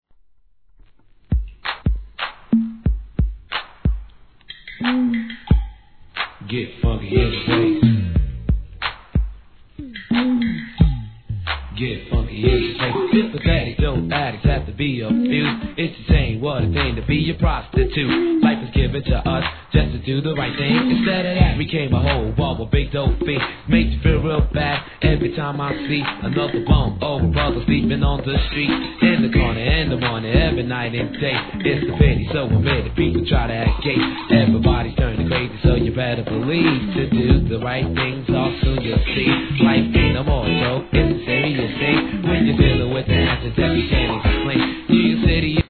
HIP HOP/R&B
COOLさのなかにFUNKYさを兼ね備えた1983年の歴史的名盤OLD SCHOOL!!